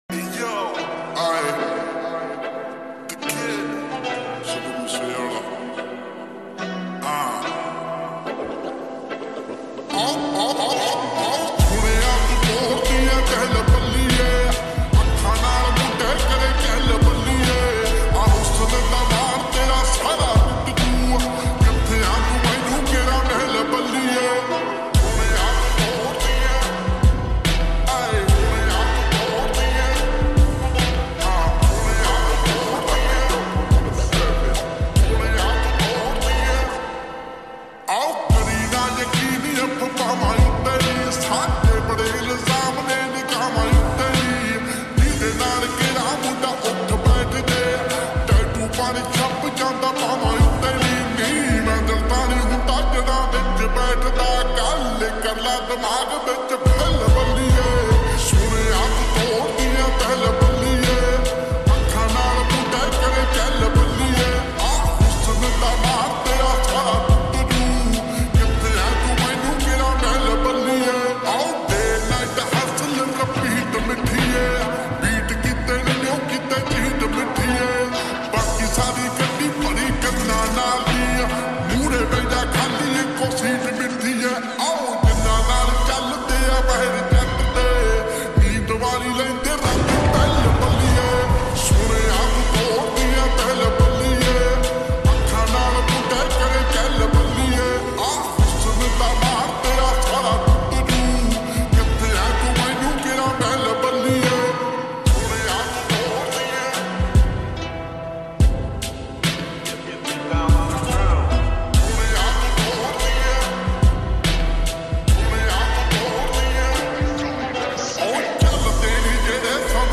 SOLVED REVERB